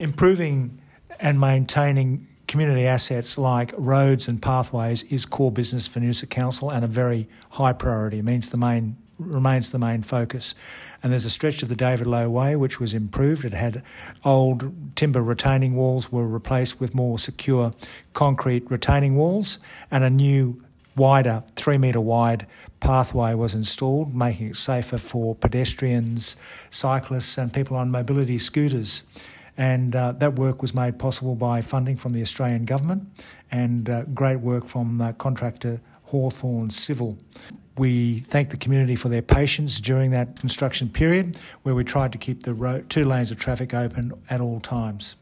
Mayor Frank Wilkie on the improved pathway and retaining wall David-Low-Way-pathway-retaining-wall-Mayor-Frank.mp3